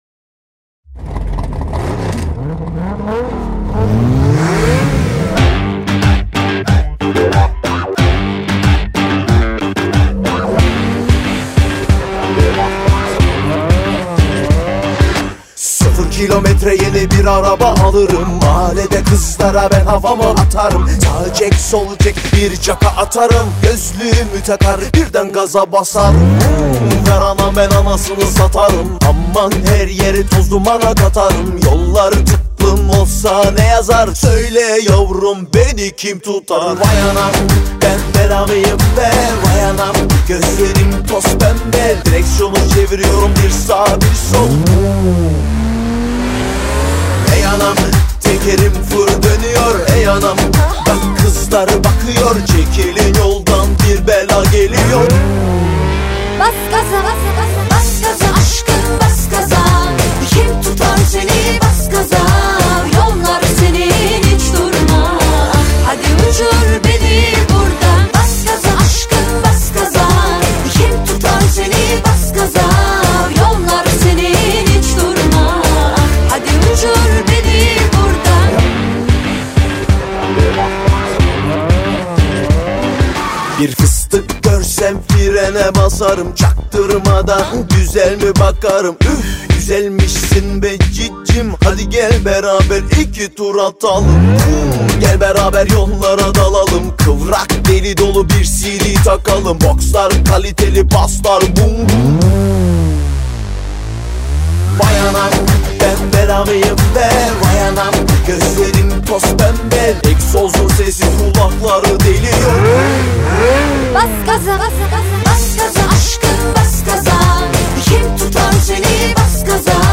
دسته بندی : دانلود آهنگ ترکی تاریخ : یکشنبه 17 مارس 2019